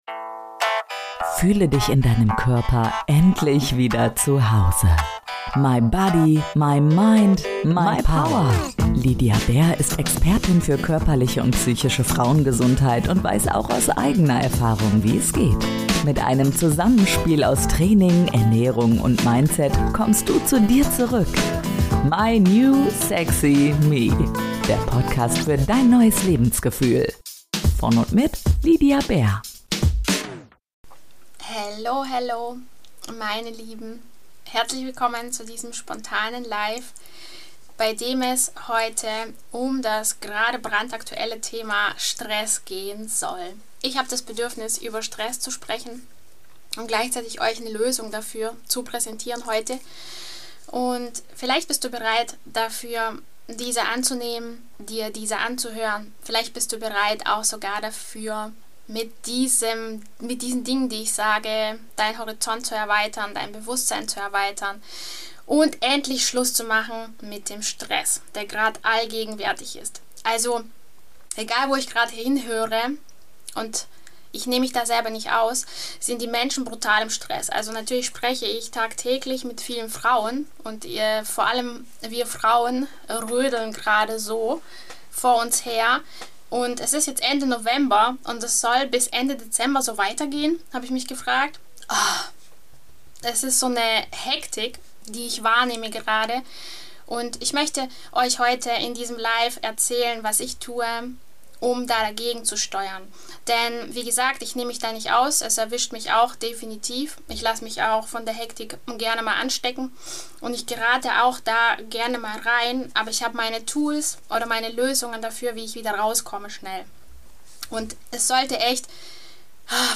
Die Lösung für Stress - hier ist sie! (Insta-Live) ~ My New Sexy Me - Der PODCAST Podcast